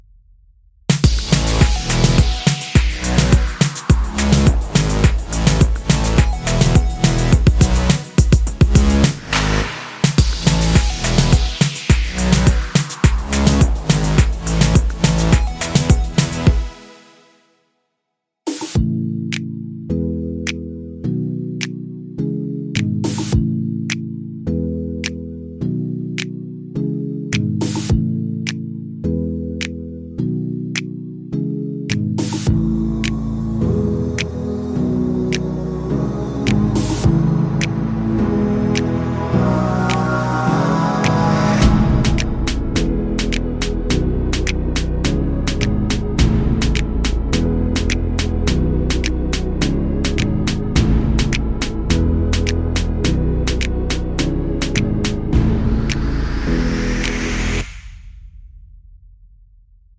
no Backing Vocals But With Aahs Pop (2010s) 3:53 Buy £1.50